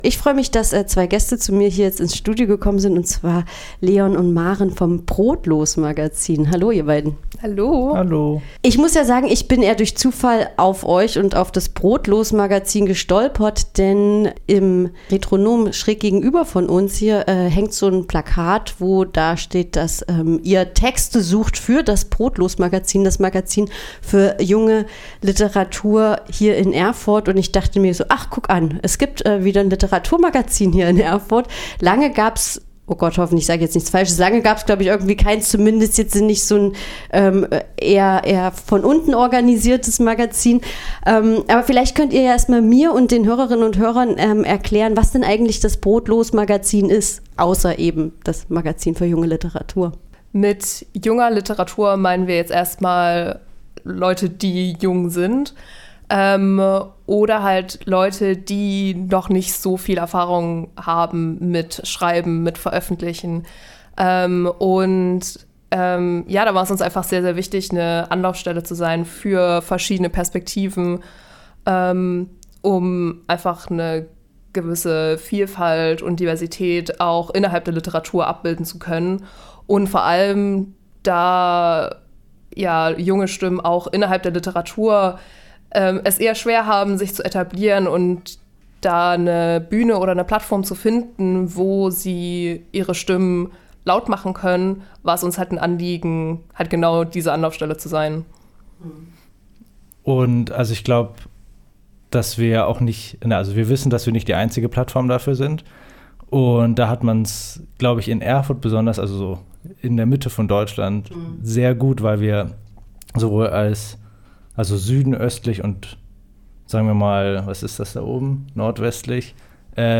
�Wir sind wach, wir sind da � und wir machen weiter.� | Interview über neues Literatur-Magazin für Erfurt